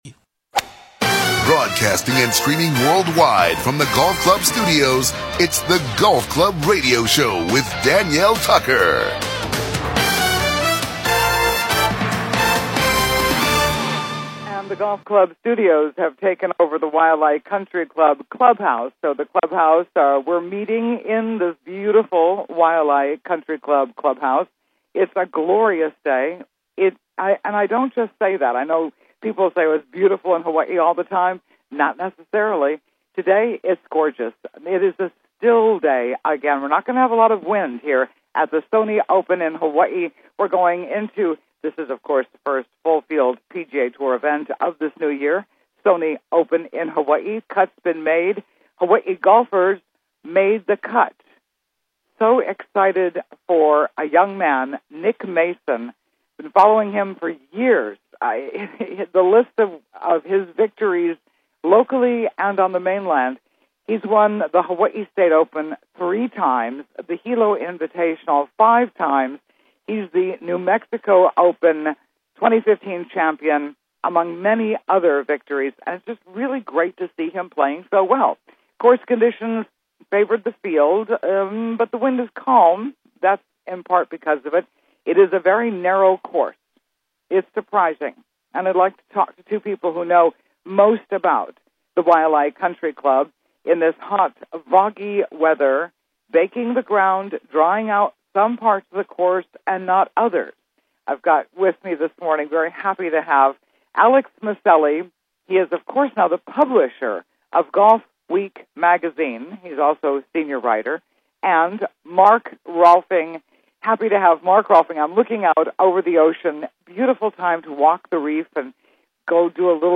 Live from The Sony Open in Hawaii The Waialae Country Club Honolulu, Hawaii
Mark Rolfing: NBC and Golf Channel Golf Analyst